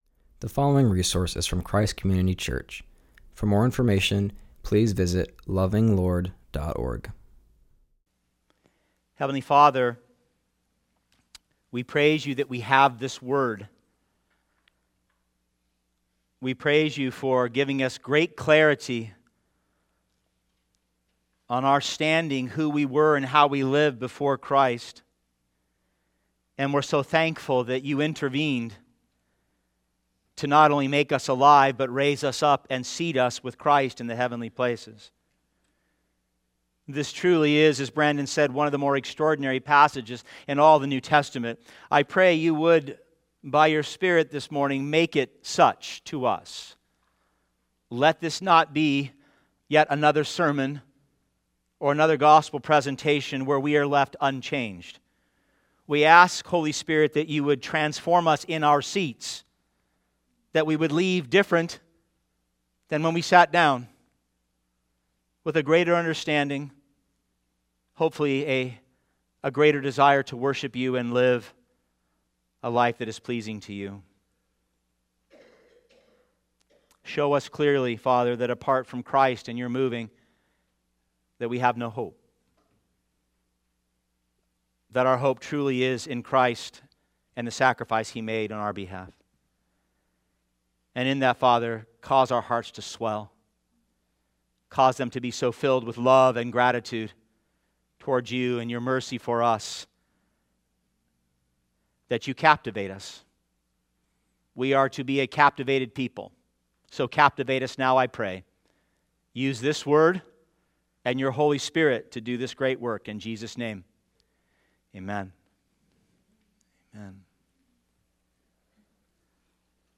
preaches from Ephesians 2:1-7.